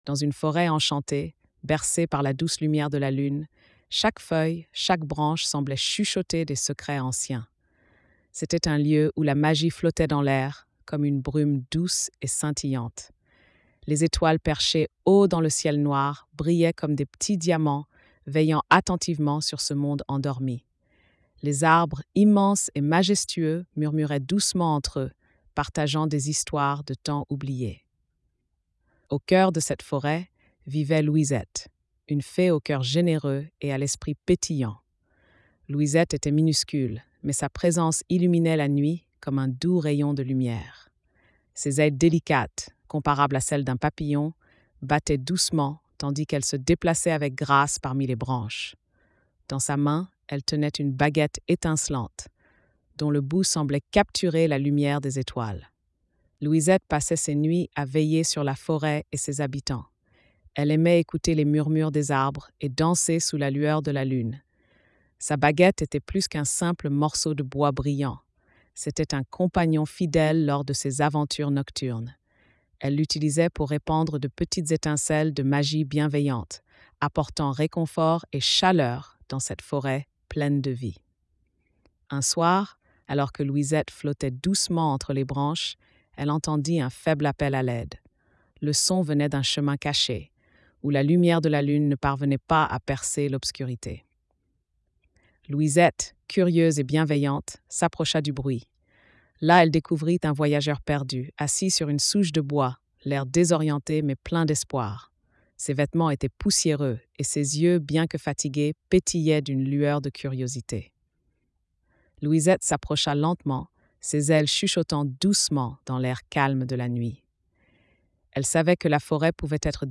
🎧 Lecture audio générée par IA
Bruitages & Musiques